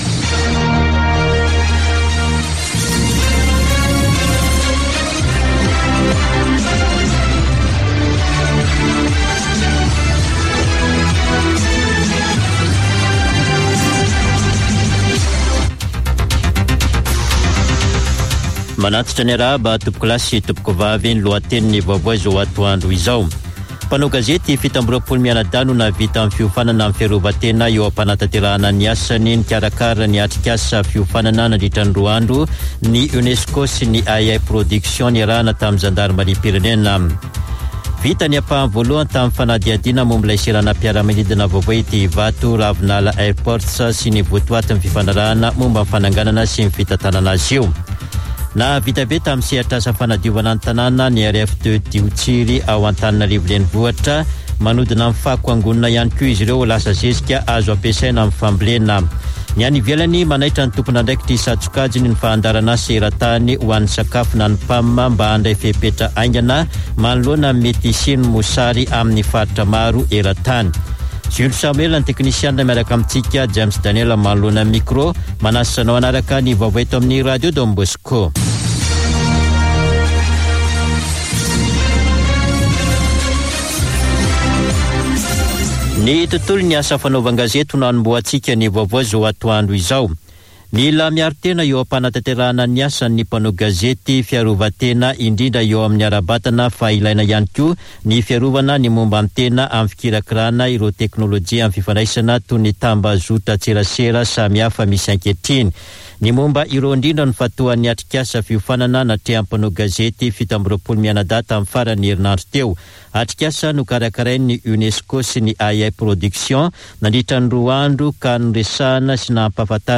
[Vaovao antoandro] Alatsinainy 08 novambra 2021